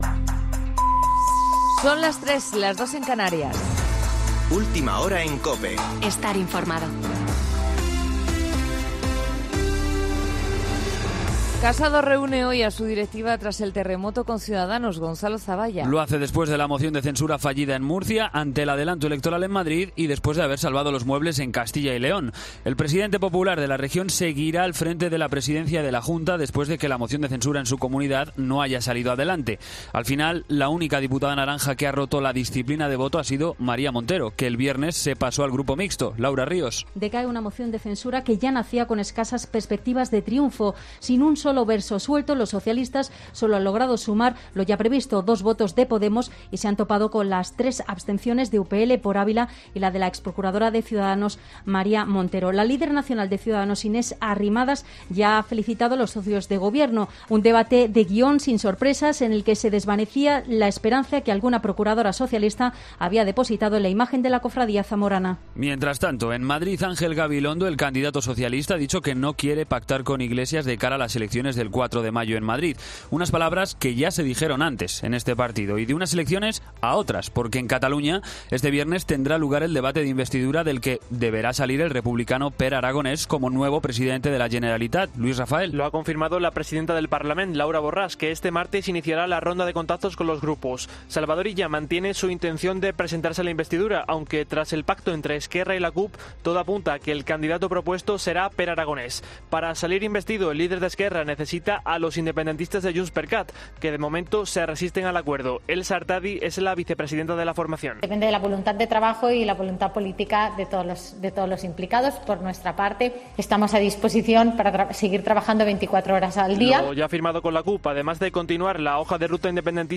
Boletín de noticias COPE del 23 de marzo de 2021 a las 03.00 horas